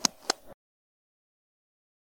simpleSwitch.wav